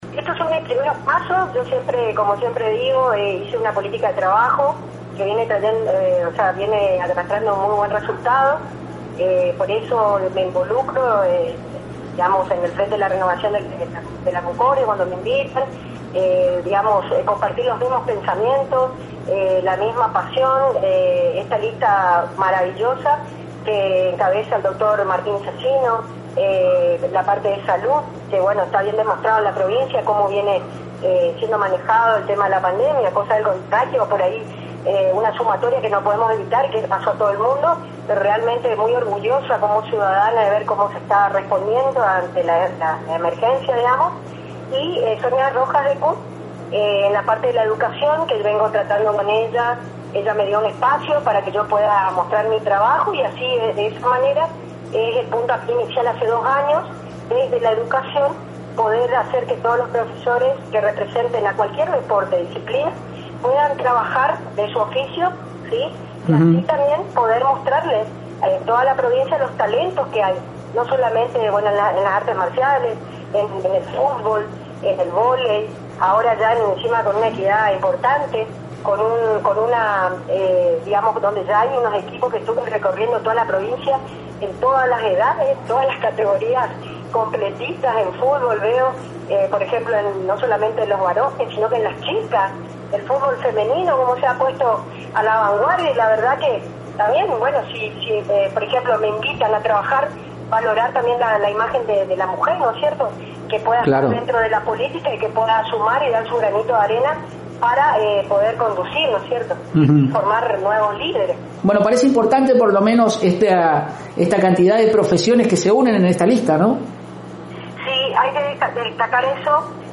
En charla exclusiva con Radio Elemental